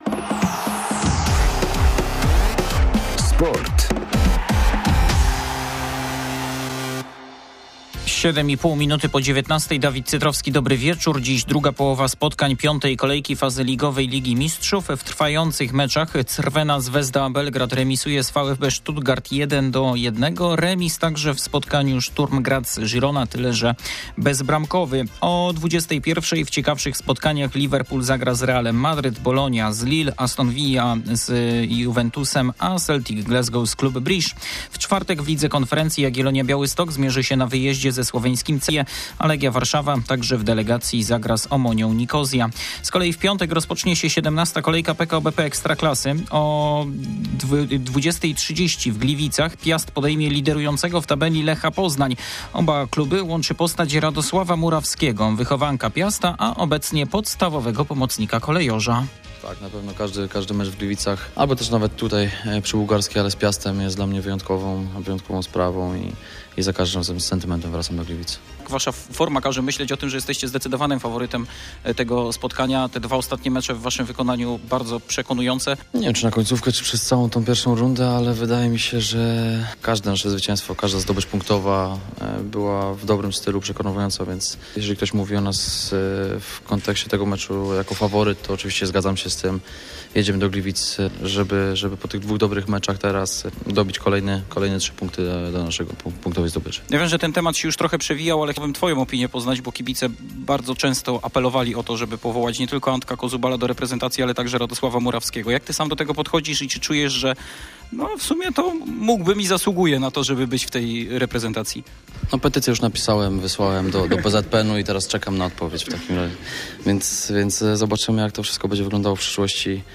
27.11.2024 SERWIS SPORTOWY GODZ. 19:05